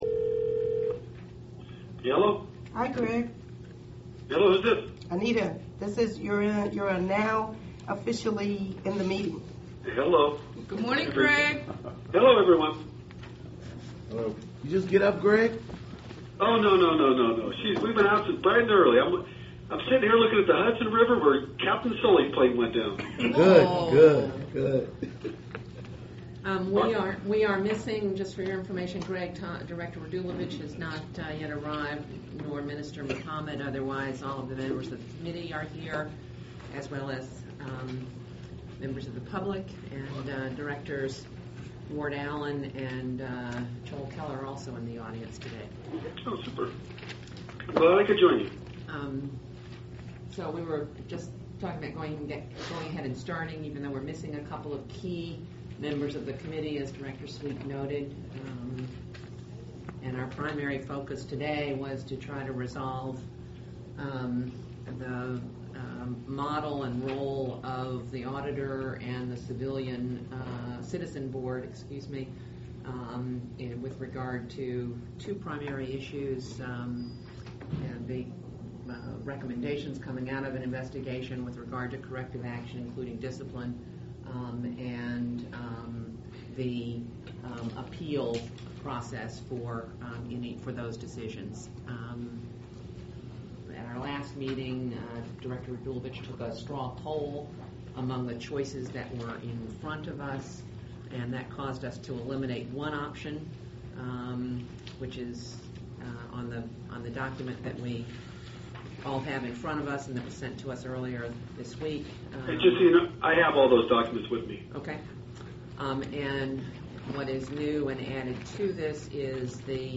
Its a really important process, and it's in direct reaction to the killing of Oscar Grant. Below is the first piece of three from the full audio of the meeting here: